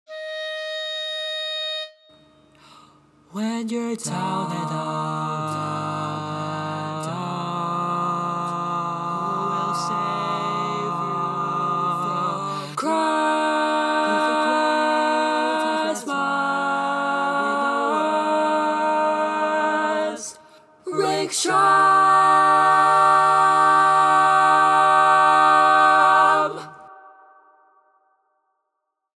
Key written in: E♭ Major
How many parts: 4
Type: Barbershop